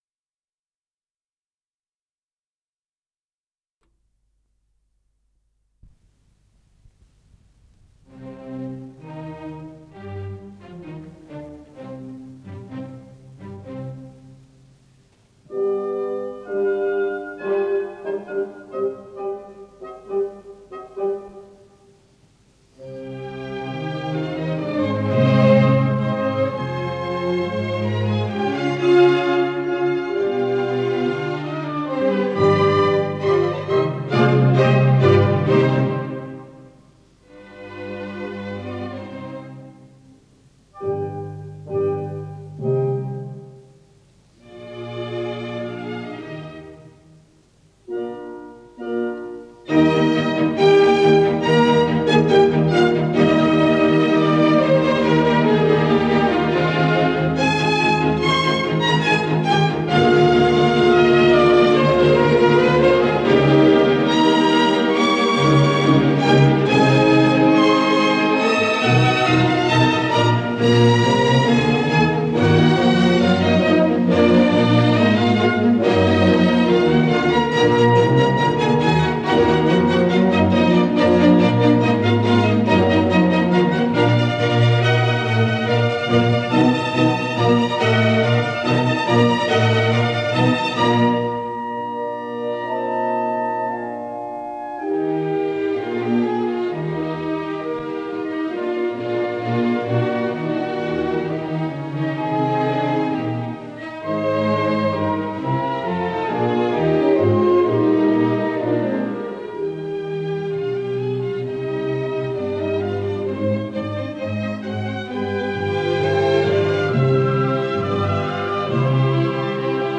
Исполнитель: Эмиль Гилельс - фортепиано
Концерт №3 для фортепиано с оркестром, соч. 37
До минор